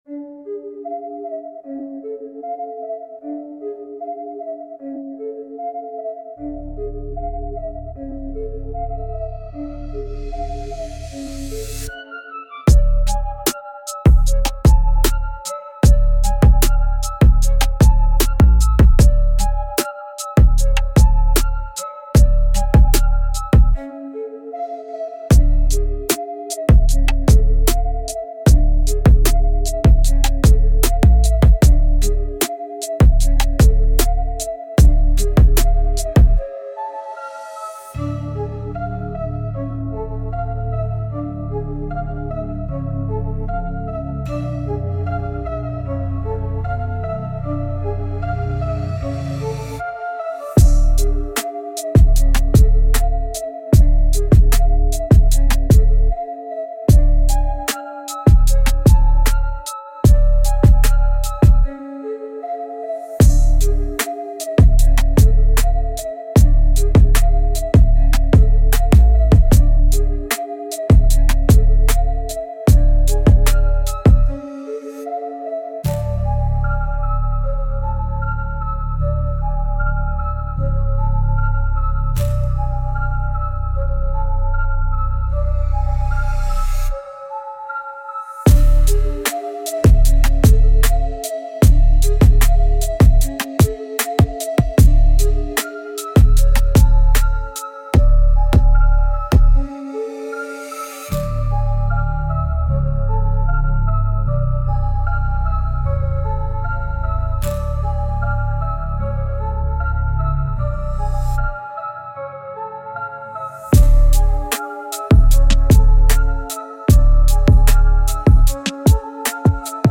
Instrumental - Real Liberty Media DOT xyz -- 4 Mins